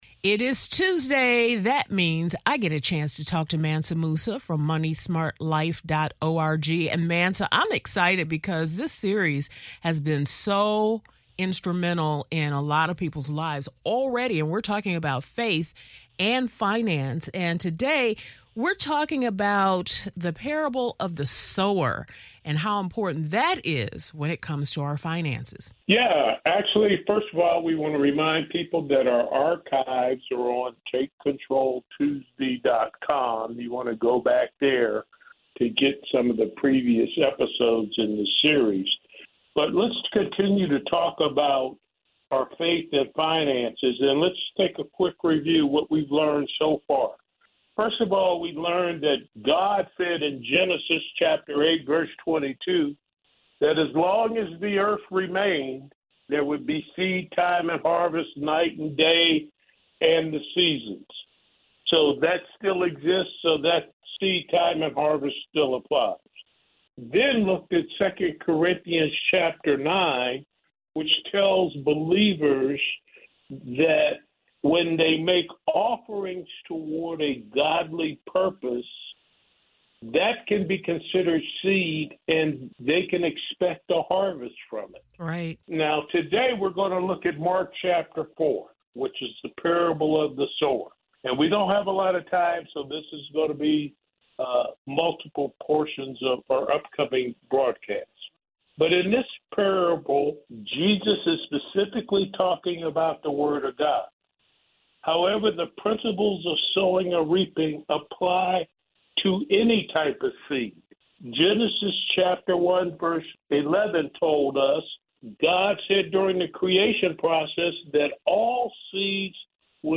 Here you will find conversations with artists, authors, speakers, and teachers within the faith-based community.